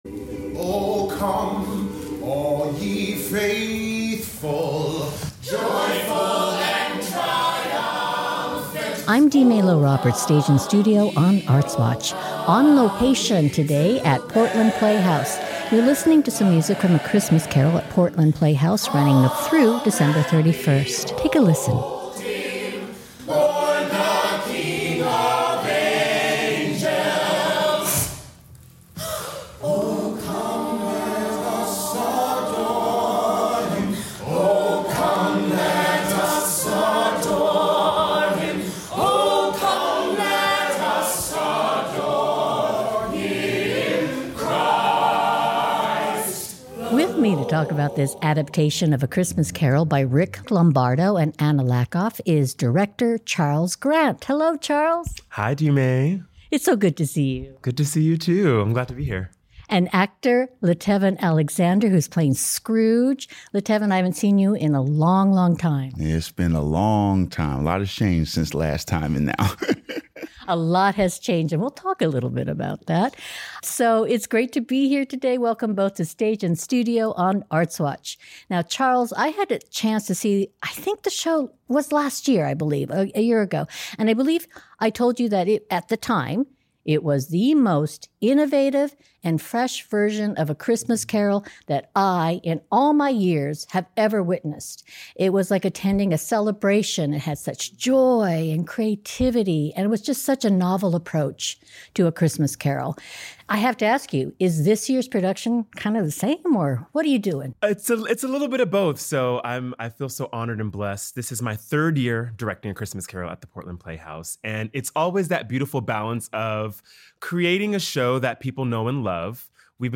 Music clip: Cast of Portland Playhouse singing “O Come O Ye Faithful.”